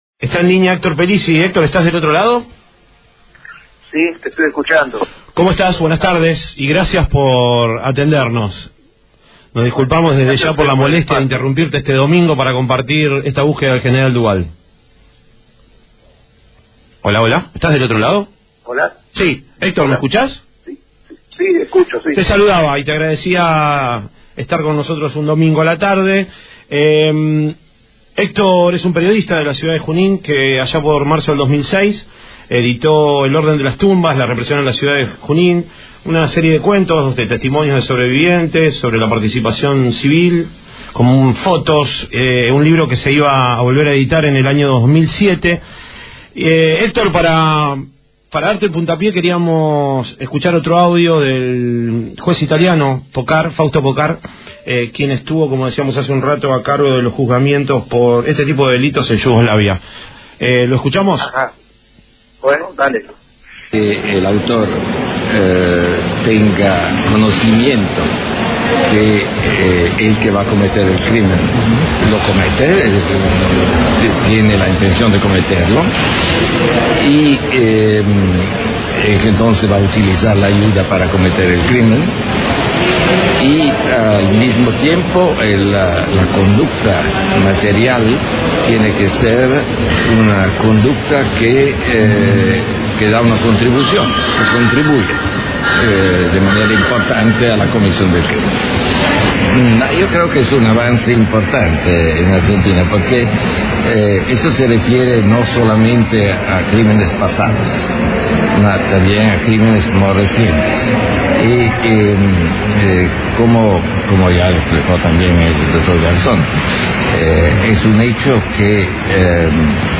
Entrevistado en Buscando al General Duval